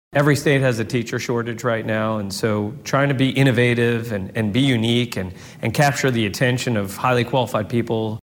CLICK HERE to listen to commentary from State Senator Adam Pugh.